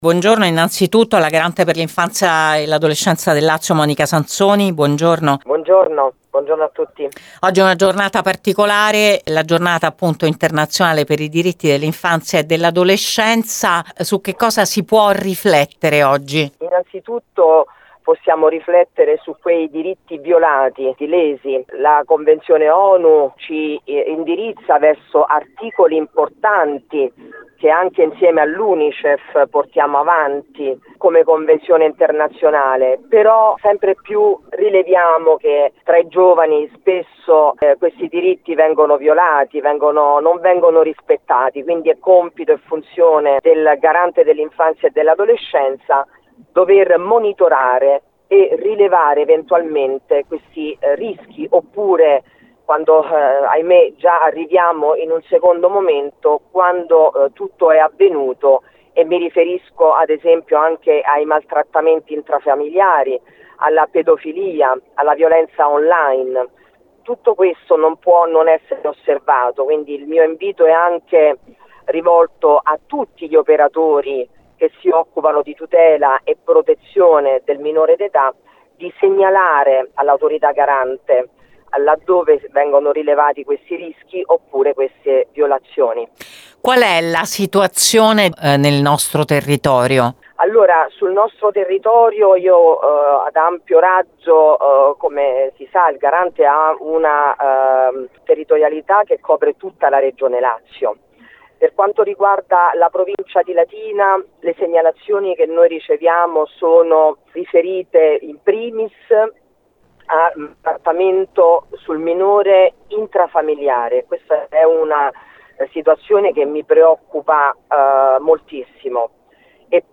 “Ancora tanti diritti violati e anche le devianze spesso sono un grido d’allarme”, ci ha raccontato a Gr Latina,  la Garante Monica Sansoni